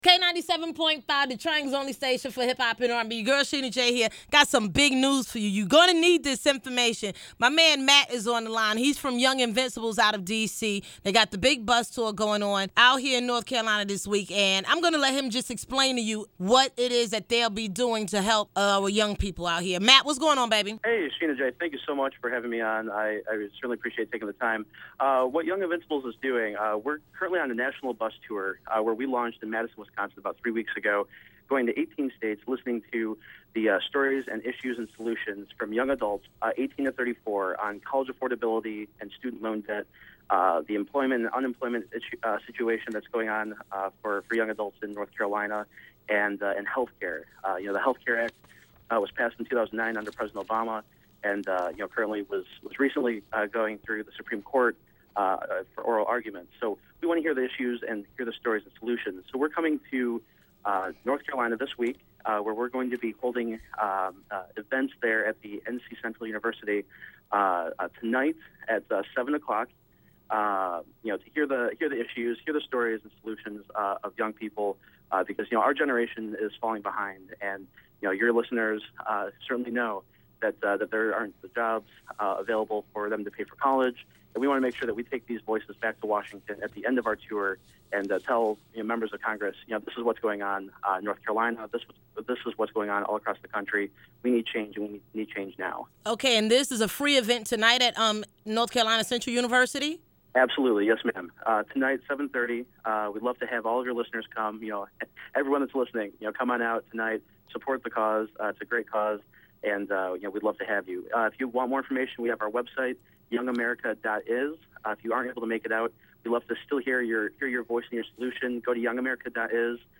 young-invincibles-interview.mp3